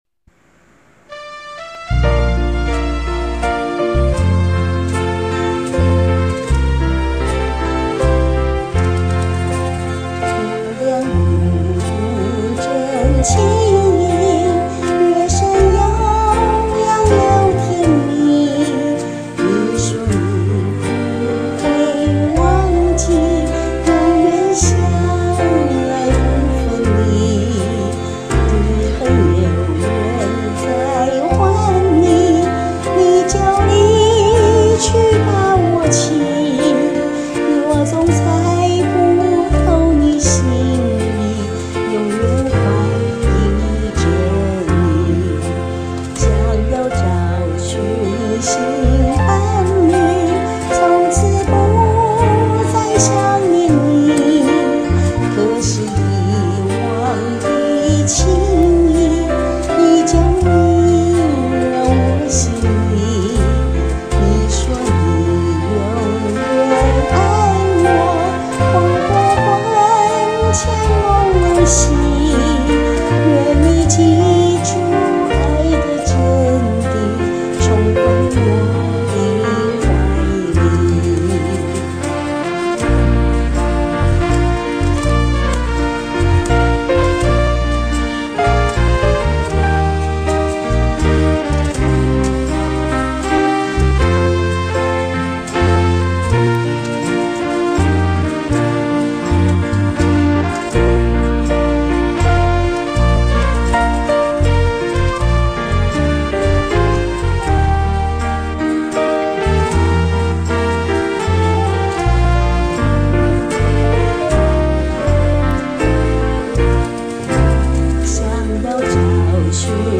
自戀歐巴桑的紅包場老歌系列啦~ ^0^